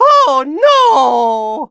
18 KB {{aboutfile |1=The sound Mario makes when he fights, wins, and takes everything.
Mario_(Oh_noooo)_-_Super_Mario_Party_Jamboree.ogg